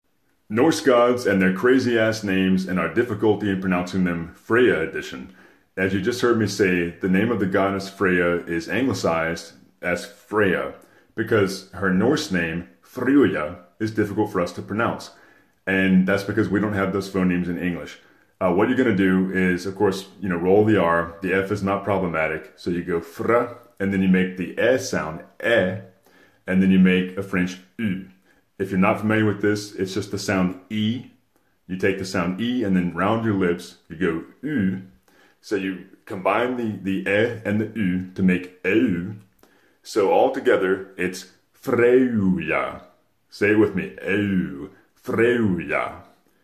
How to pronounce "Freyja": the sound effects free download